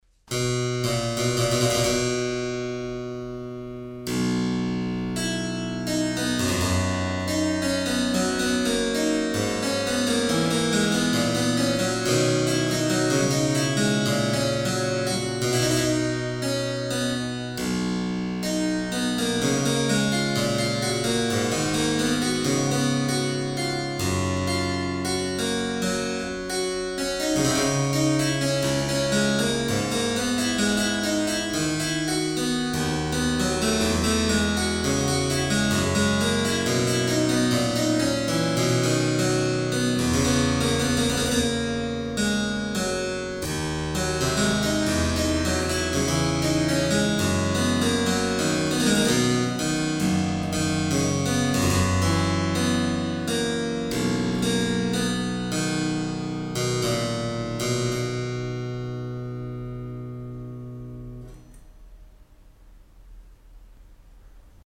clavicembalo